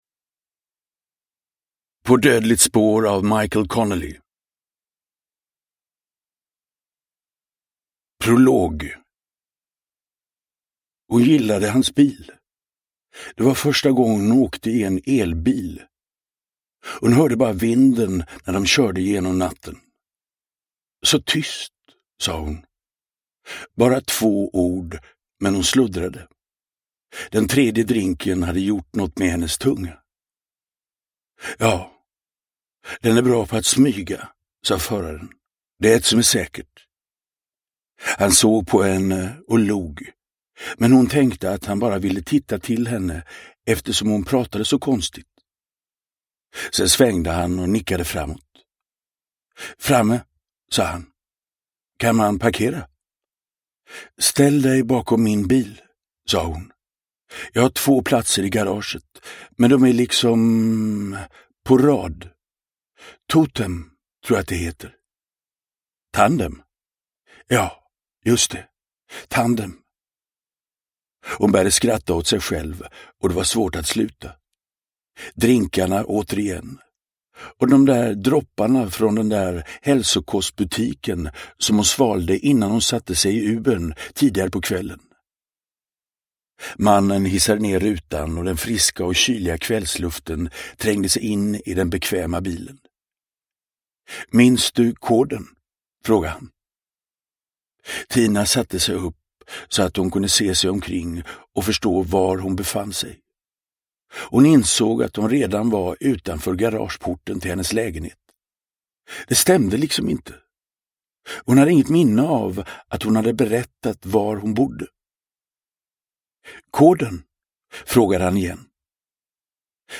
På dödligt spår – Ljudbok – Laddas ner
Uppläsare: Magnus Roosmann